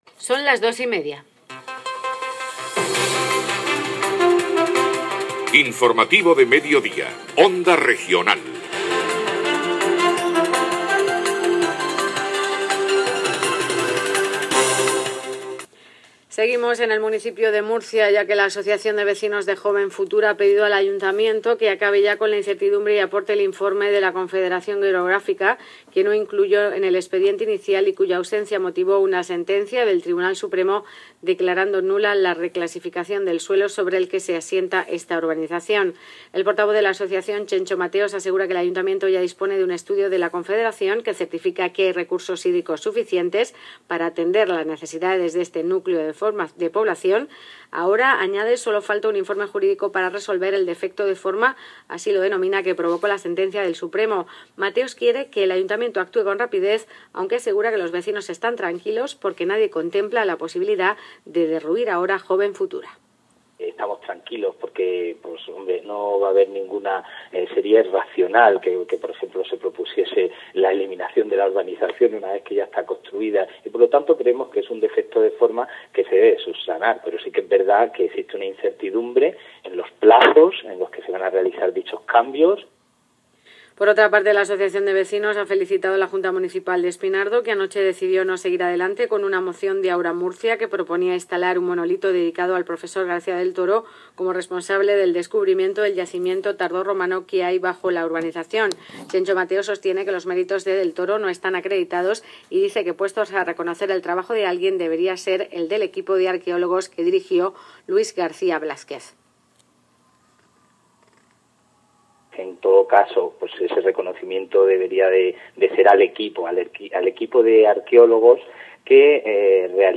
Declaraciones en Onda Regional de Murcia: